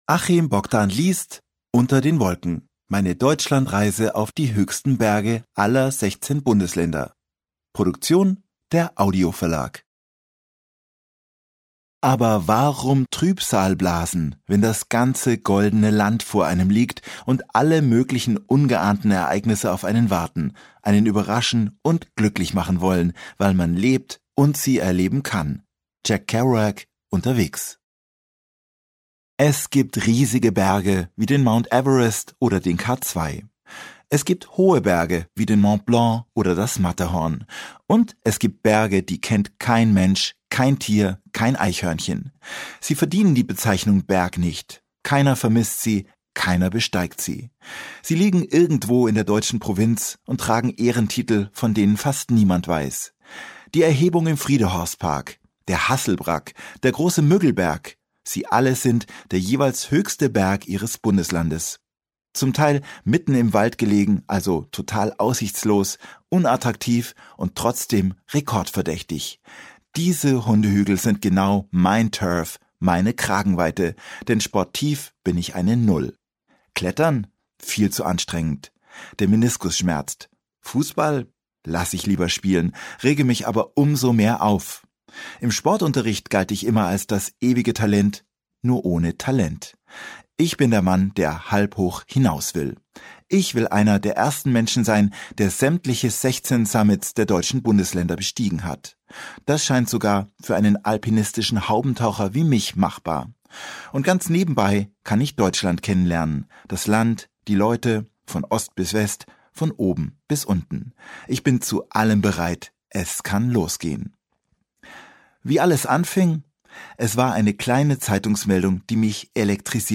Ungekürzte Autorenlesung (2 mp3-CDs)